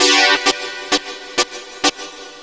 SYNTHLOOP1-R.wav